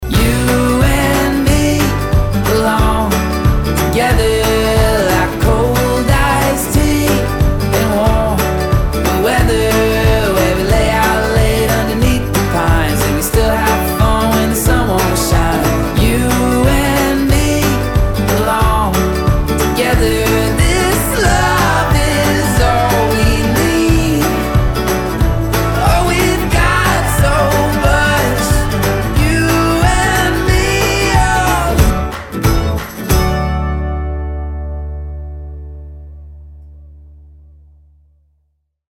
Pop & Rock
Pop